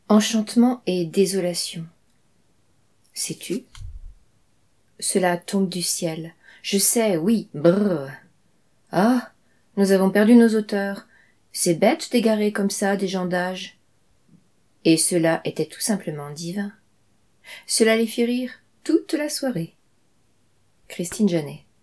Carte postale, lue par